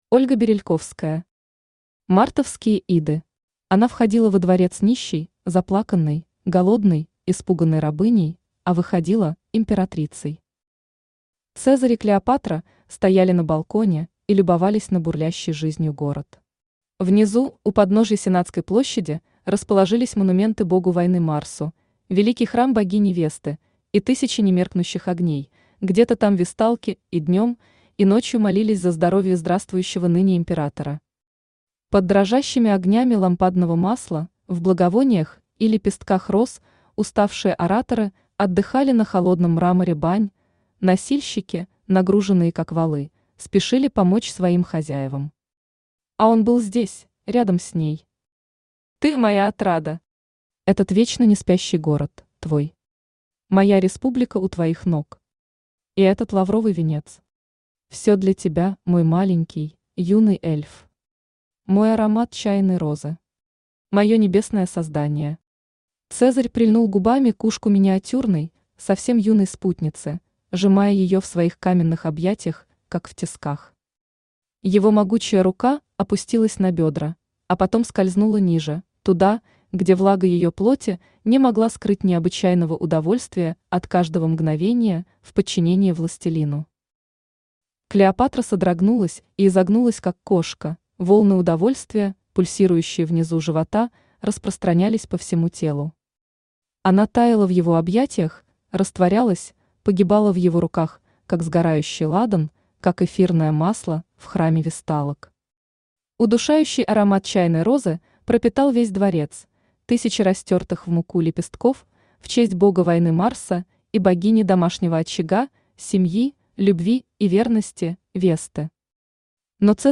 Аудиокнига Мартовские Иды | Библиотека аудиокниг
Aудиокнига Мартовские Иды Автор Ольга Ильинична Берельковская Читает аудиокнигу Авточтец ЛитРес.